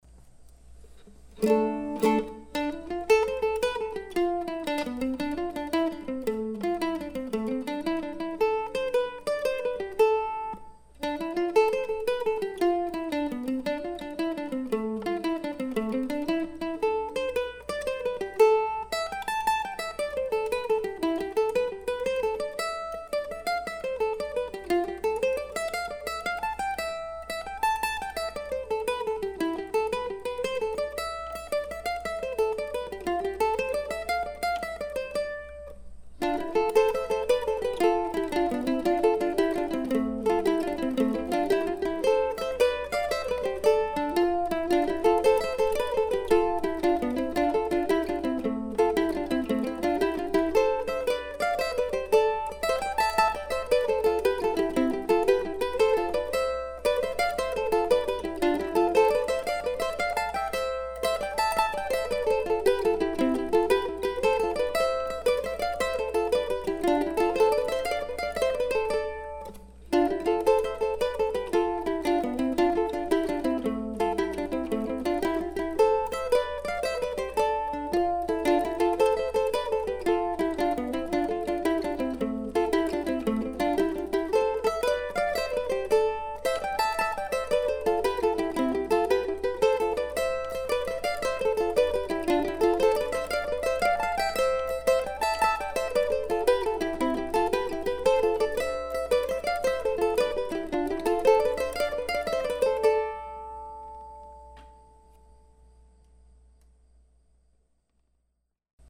I recorded this little jig a couple of weeks ago.